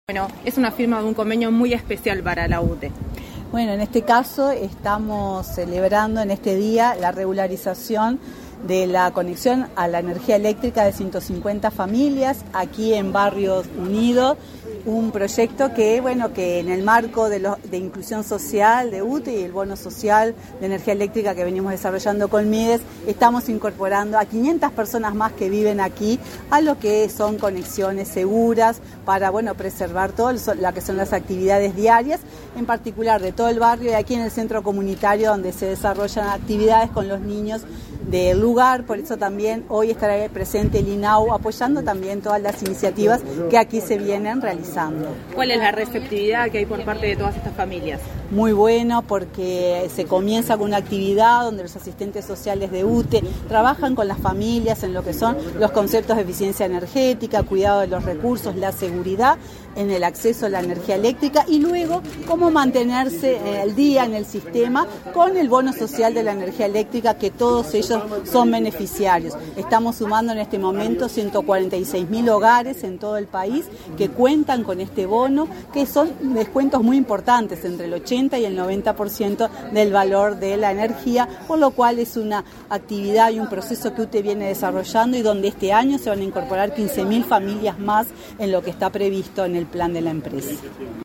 Entrevista a la presidenta de UTE, Silvia Emaldi
Entrevista a la presidenta de UTE, Silvia Emaldi 29/03/2023 Compartir Facebook X Copiar enlace WhatsApp LinkedIn Tras participar en la inauguración de obras de electrificación de UTE en el barrio Unidos, en Montevideo, este 29 de marzo, la presidenta del organismo, Silvia Emaldi, realizó declaraciones a Comunicación Presidencial.